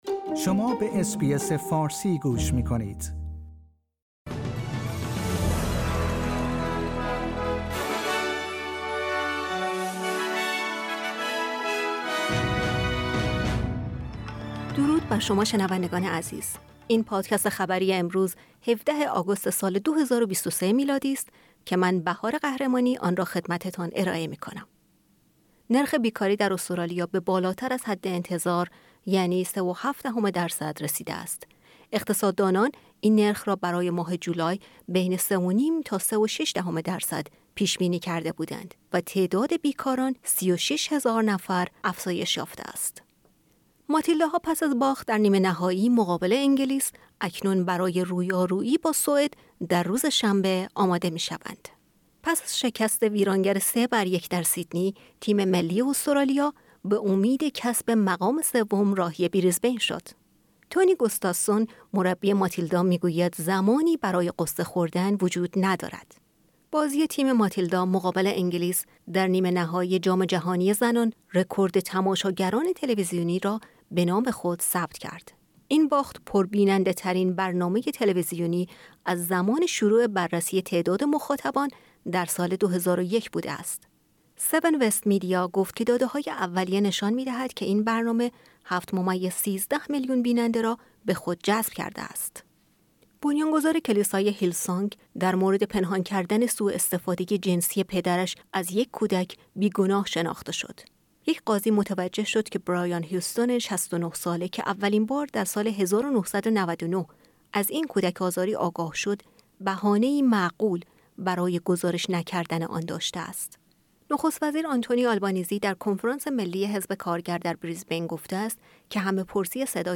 در این پادکست خلاصه‌ای از مهمترین اخبار استرالیا در روز پنج شنبه هفدهم آگوست ۲۰۲۳ ارائه شده است.